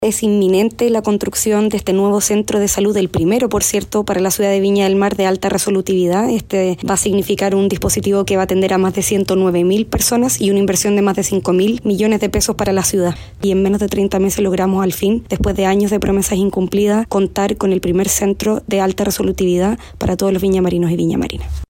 Así lo confirmó la Alcaldesa de Viña del Mar, Macarena Ripamonti